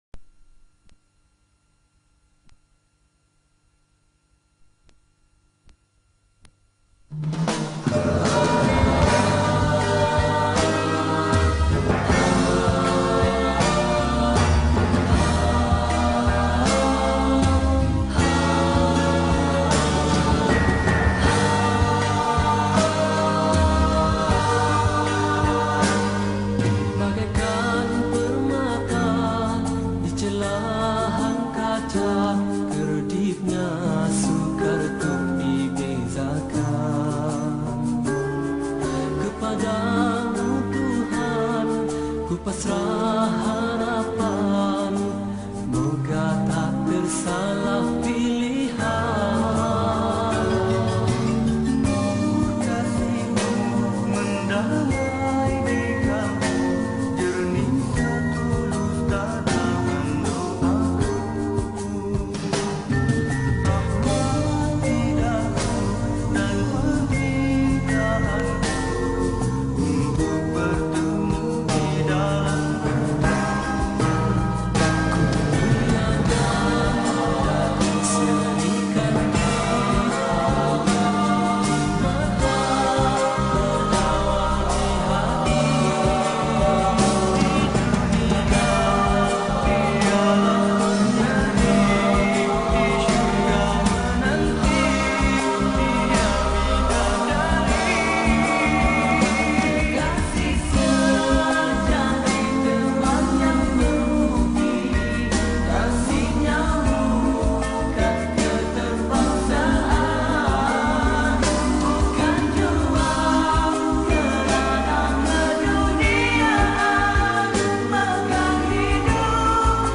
Nasyid Songs